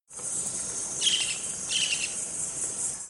Narrow-billed Woodcreeper (Lepidocolaptes angustirostris)
Detailed location: Selva Iryapú (600 hectáreas)
Condition: Wild
Certainty: Recorded vocal